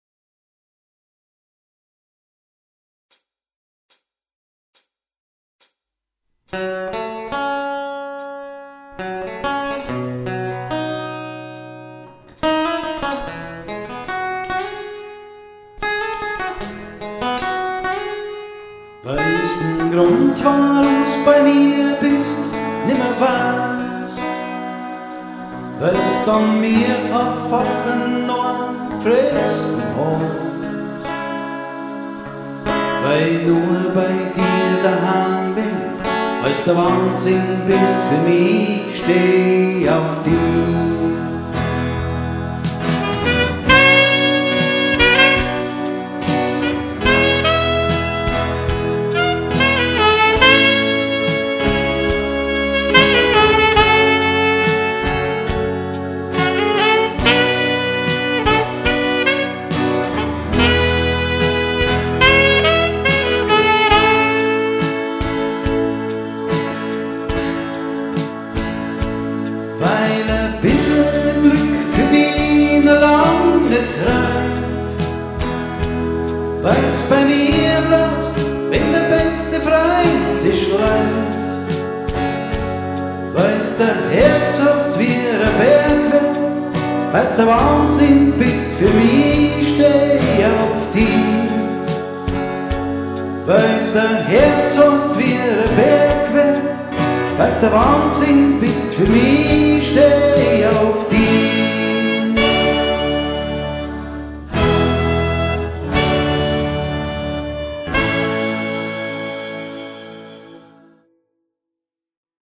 • Coverband
• Allround Partyband